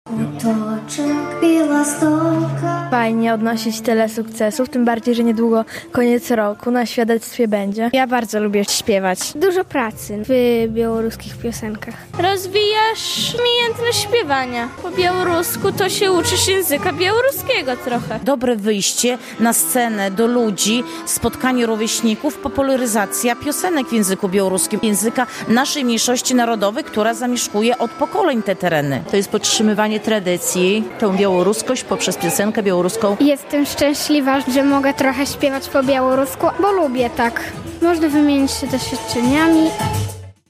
Soliści, duety, ale też zespoły i chóry prezentowały się w środę (21 05) na scenie Domu Kultury w Bielsku Podlaskim. Odbyły się tam centralne eliminacje konkursu „Piosenka białoruska” dla przedszkoli i szkół podstawowych.